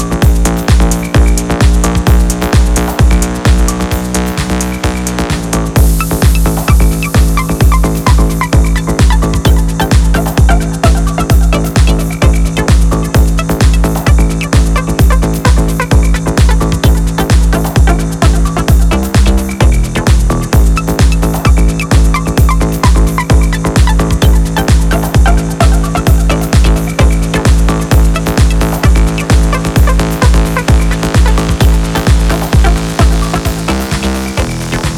Techno Dance
Жанр: Танцевальные / Техно